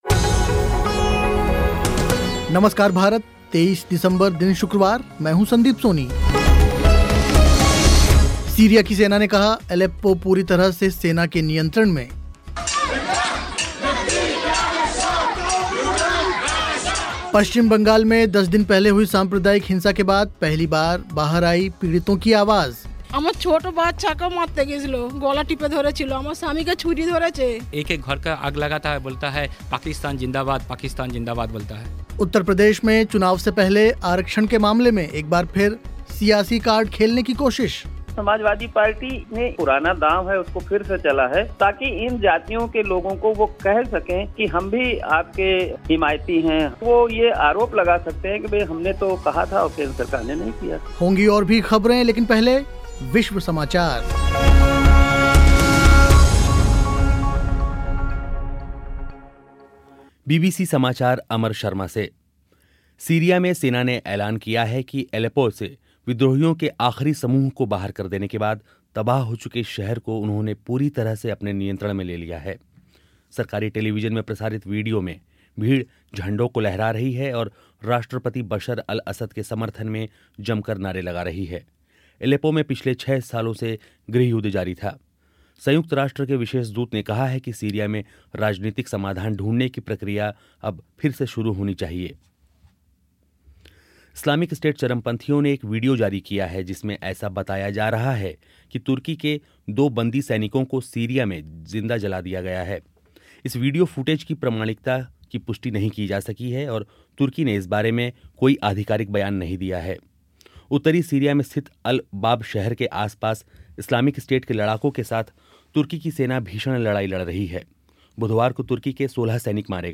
सीरिया की सेना ने कहा एलेप्पो पूरी तरह से सेना के नियंत्रण में. पं. बंगाल में दस दिन पहले हुई साम्प्रदायिक हिंसा के बाद पहली बार बाहर आई पीड़ितों की आवाज़. उत्तर प्रदेश में चुनाव से पहले आरक्षण के मामले में एक बार फिर सियासी कार्ड खेलने की कोशिश. होंगी खेल की ख़बरें और समाचार पत्रों की सुर्खियां भी.